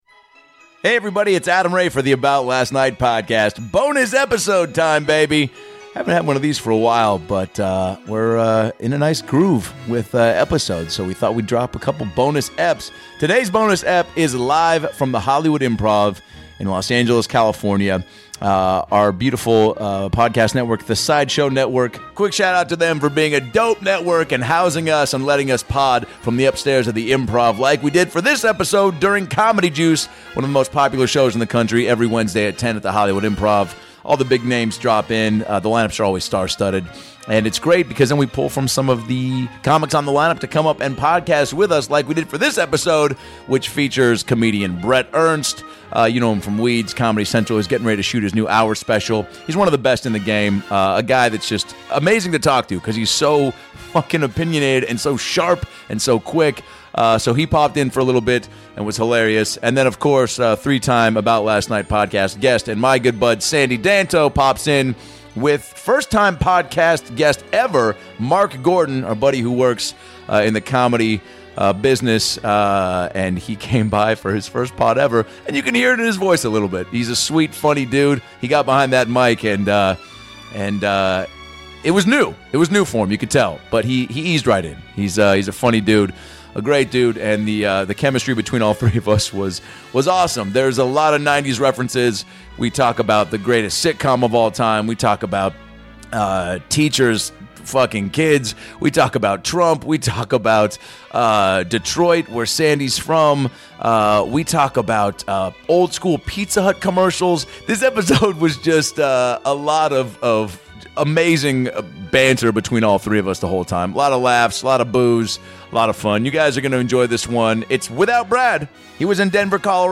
This episode has so many laughs per minute, and 90's references per second!